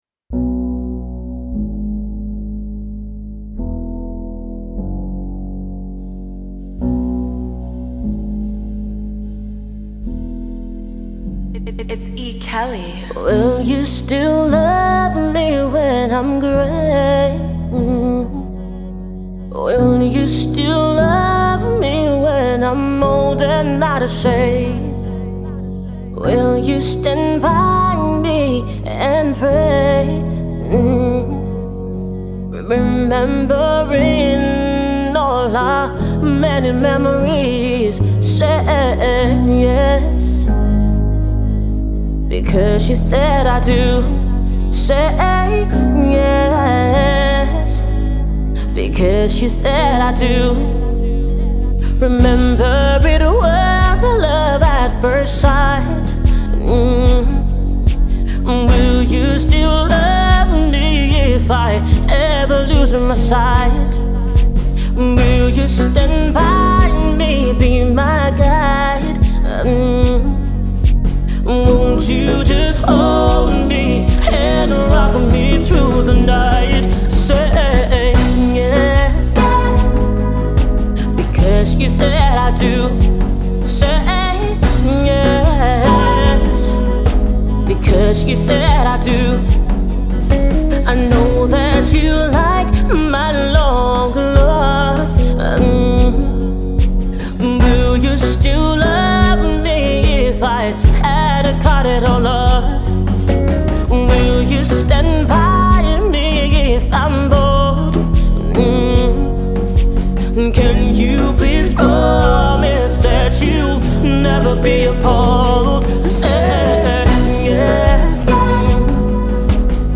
a soothing song that will help get into your weekend
a sincere love song for everyone